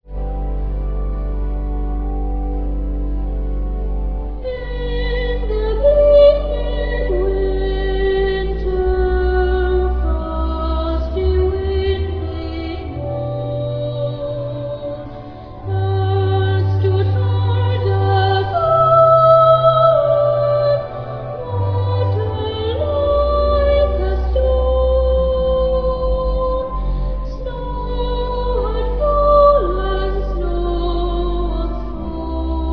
boy soprano
tenor
organ.